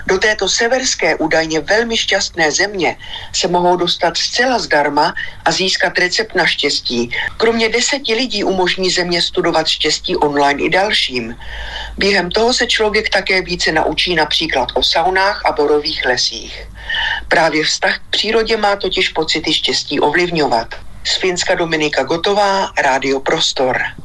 reportáž